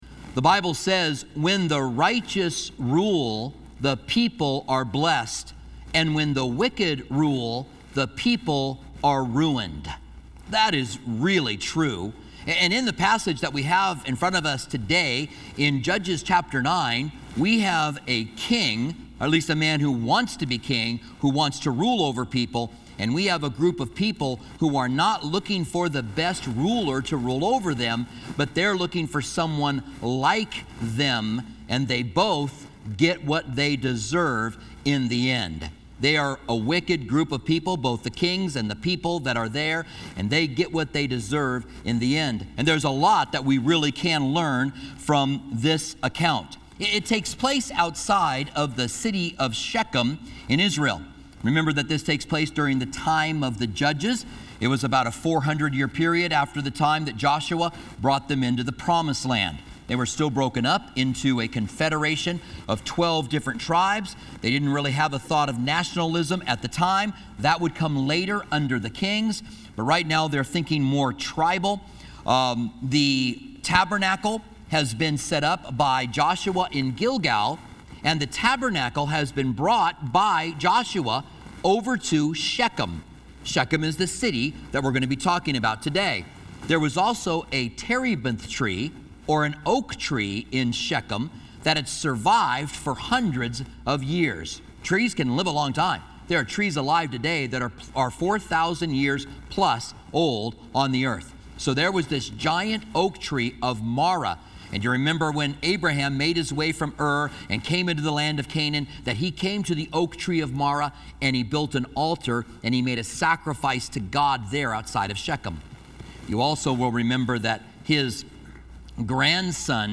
Commentary on Judges